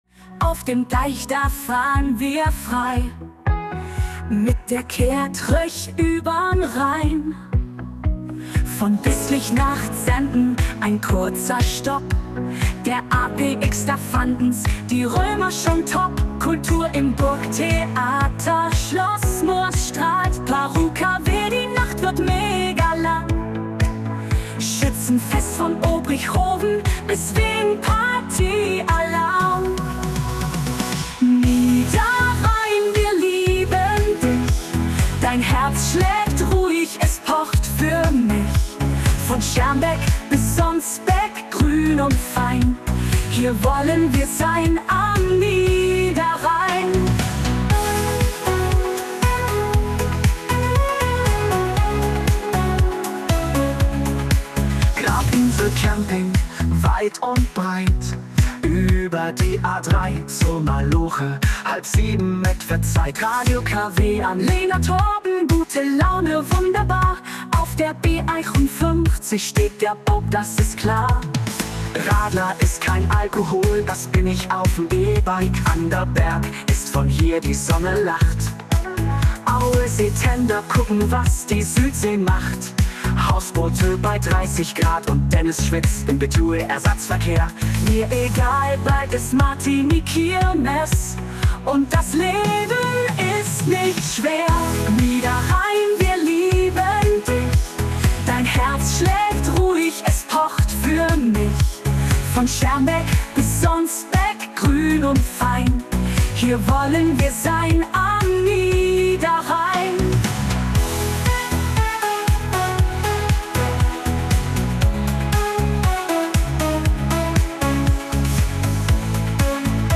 eine Hymne für den Niederrhein
Den KI-Song "Wir im Kreis Wesel" von "Unsere Hörer" könnt ihr hier nachhören und mitsingen.